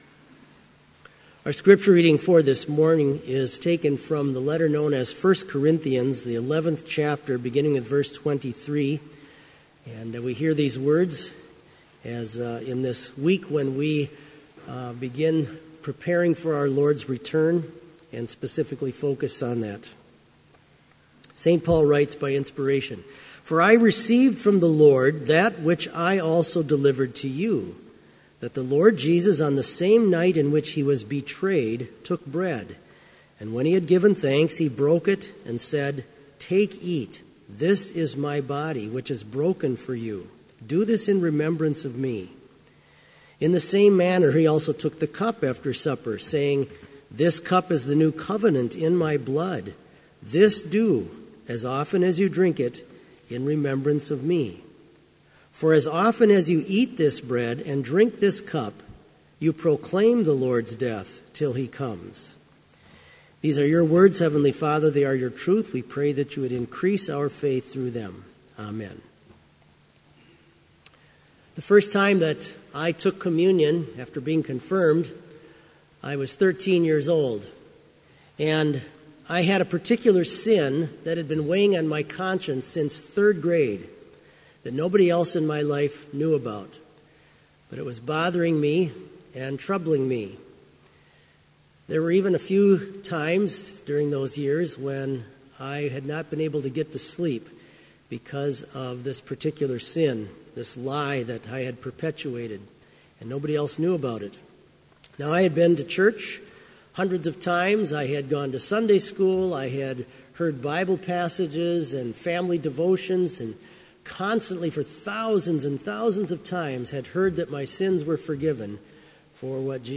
Complete service audio for Chapel - November 11, 2016